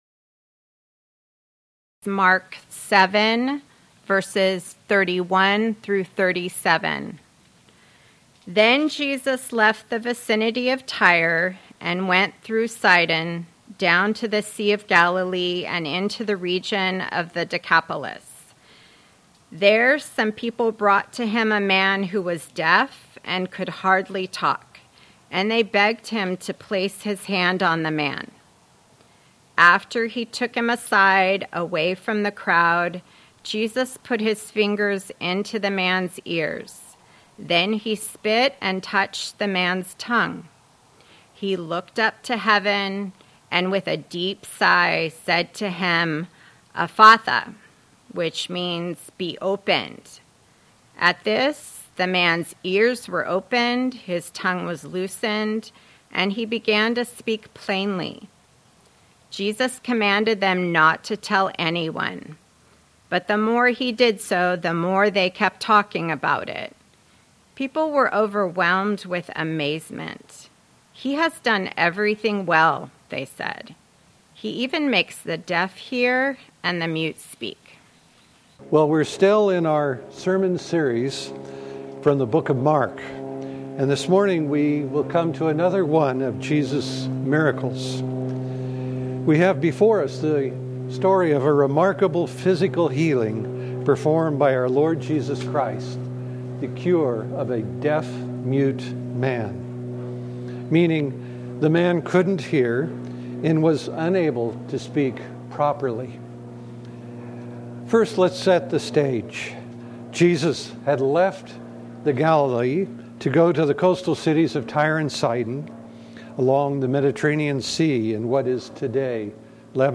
More Sermons From the book of Mark